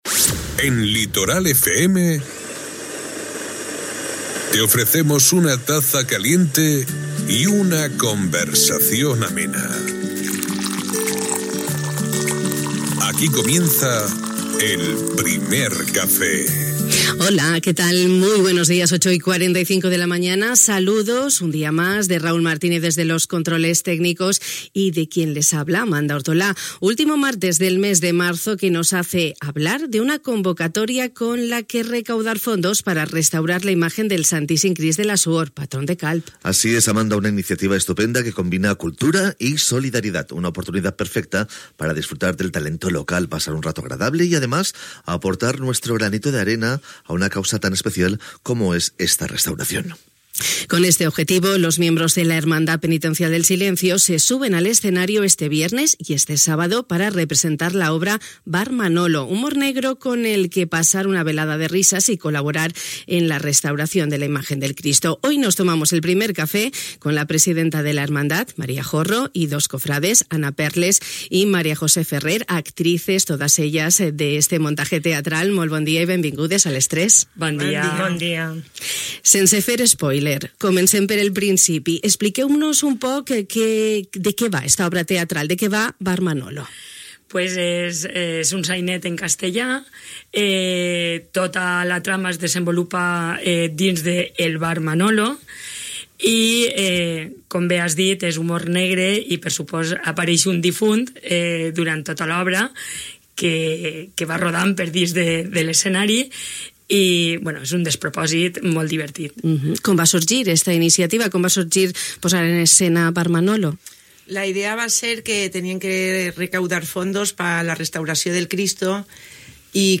Com ens han explicat les nostres convidades altres col·lectius locals es volen sumar al projecte de finançament de la restauració del Santíssim Crist de la Suor.